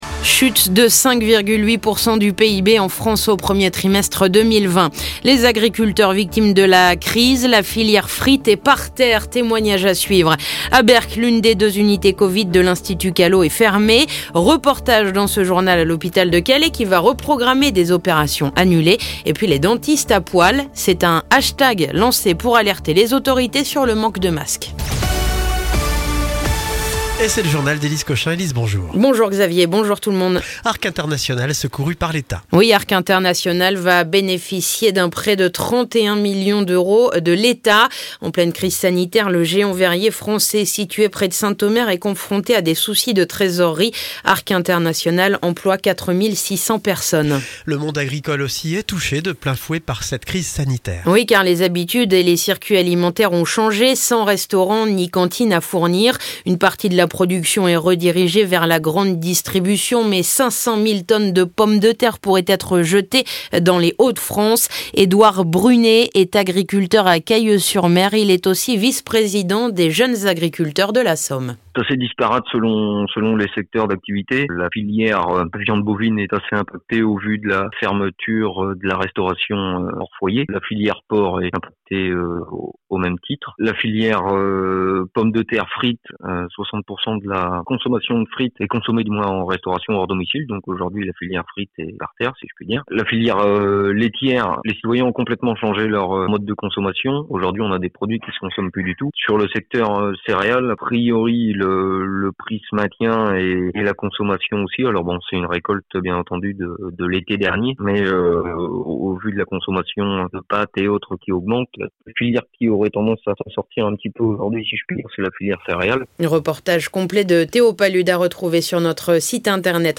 Le journal du jeudi 30 avril